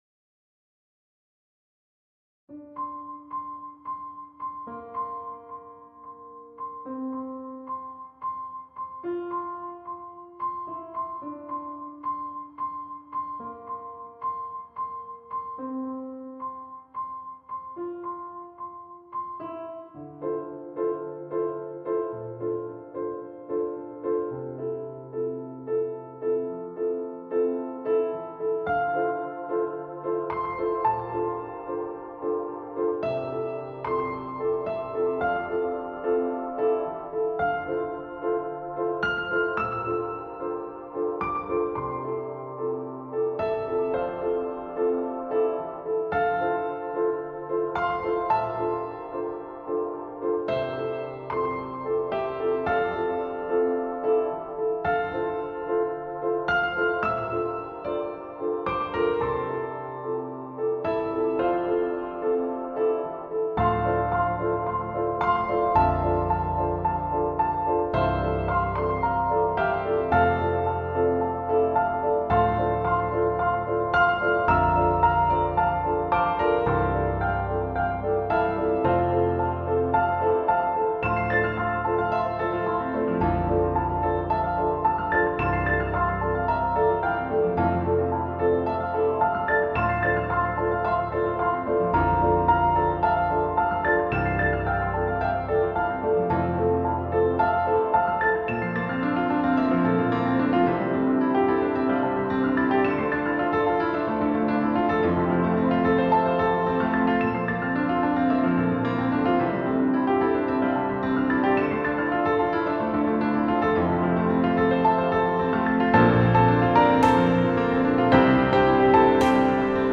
piano 50 minutes compilation ag chill out study mix.mp3
piano-50-minutes-compilation-ag-chill-out-study-mix.mp3